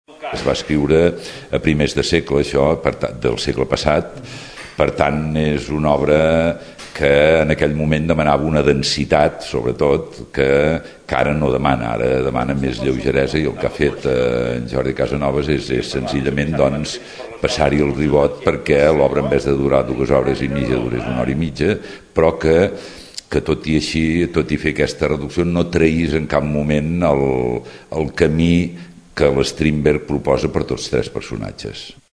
L’actor Lluís Soler parlava sobre el context de l’obra.